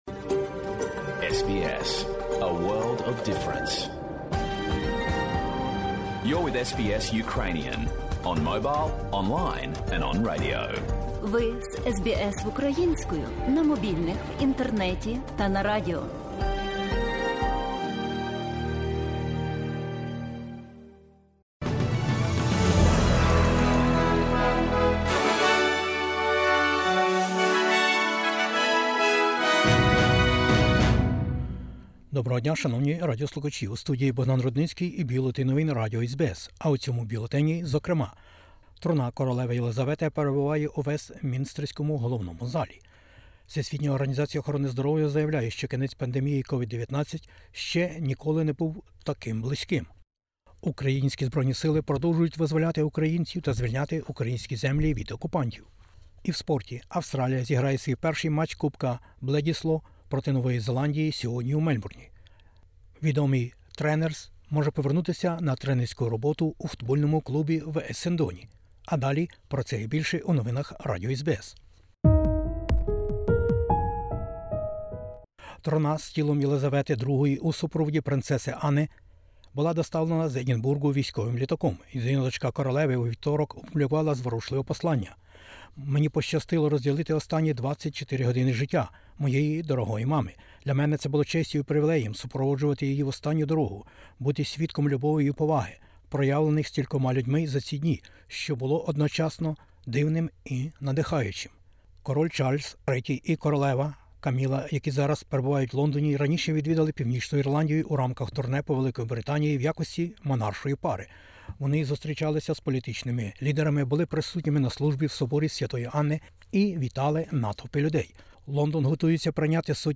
Бюлетень SBS новин - 15/09/2022